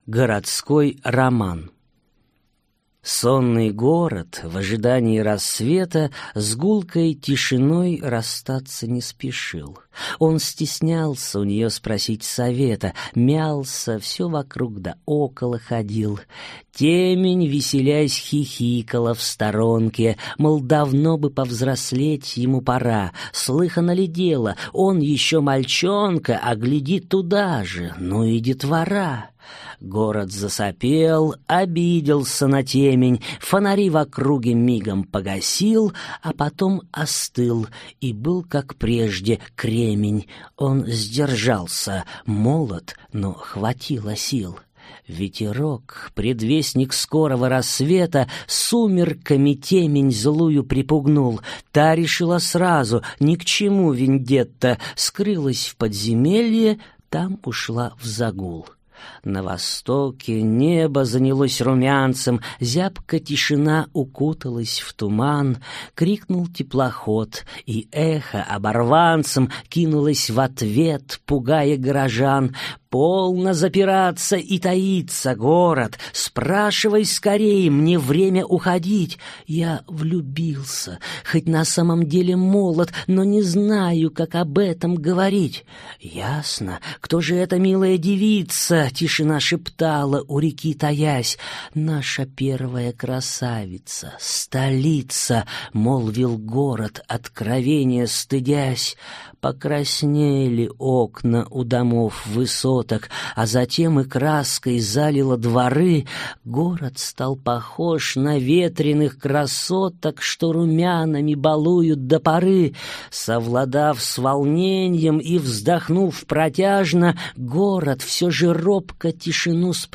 Аудиокнига Перелетные души любви (сборник) | Библиотека аудиокниг